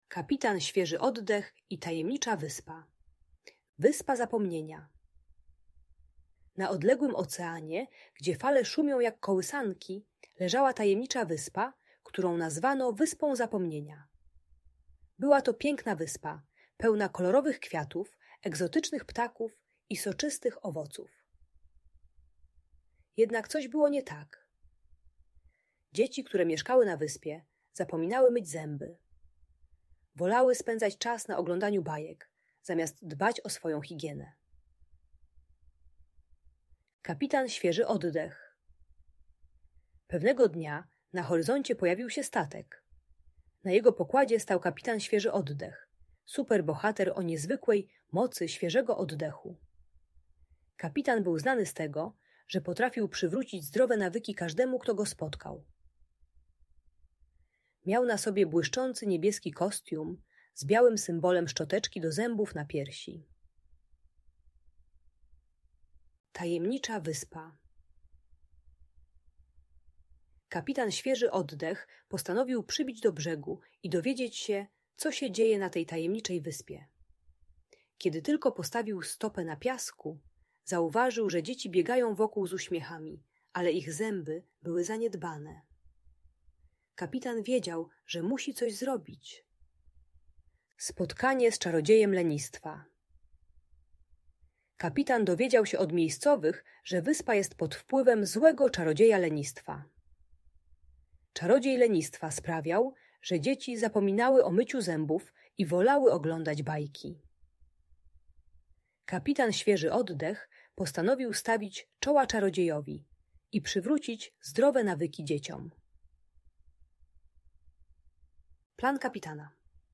Kapitan Świeży Oddech i Tajemnicza Wyspa - story - Audiobajka